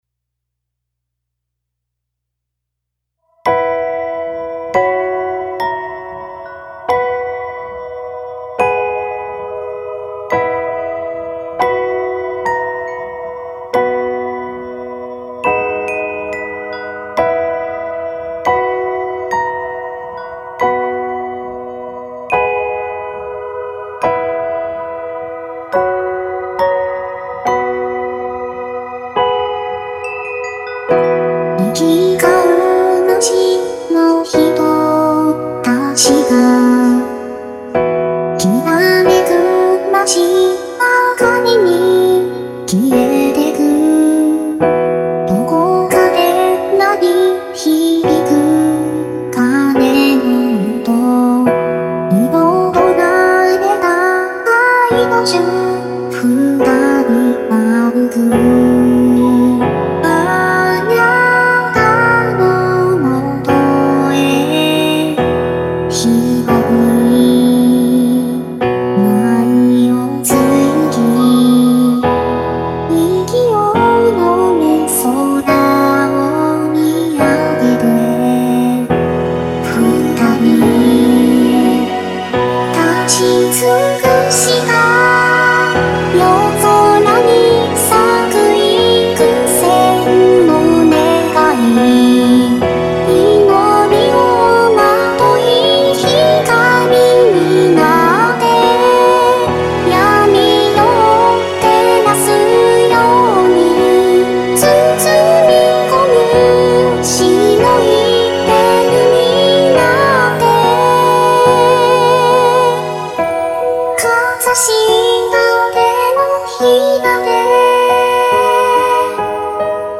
ピアノバラードを軸にアコースティックな楽器とシンセサイザーでアレンジしています。